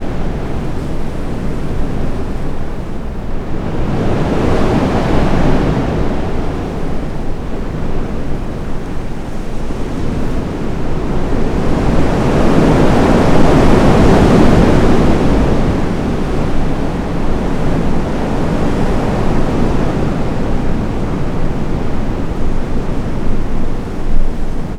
fan_loop.wav